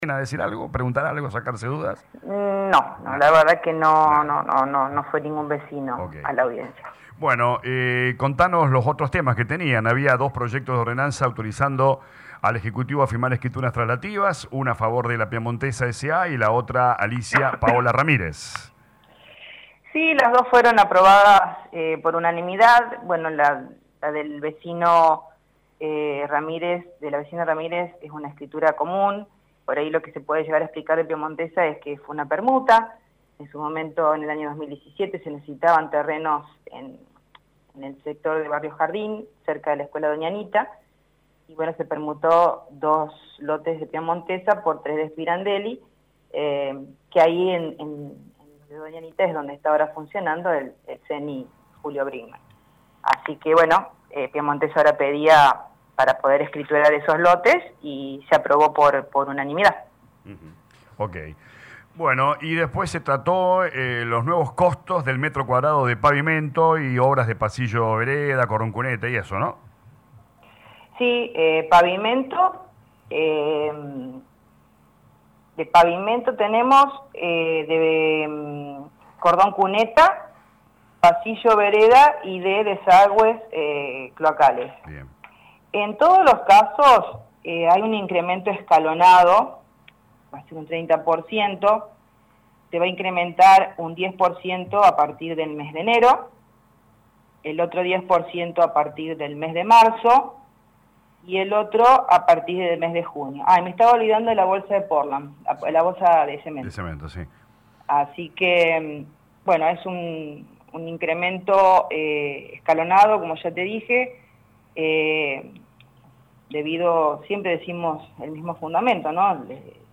La Presidente Ivana Dalmazzo explicó a LA RADIO 102.9 los nuevos cuadros tarifarios y los demás puntos tratados en el orden del día: